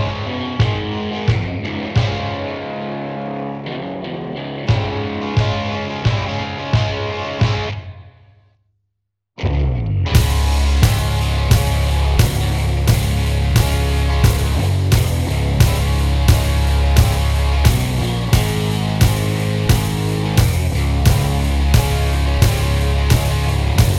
Down One Semitone Rock 3:31 Buy £1.50